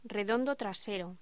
Sonidos: Voz humana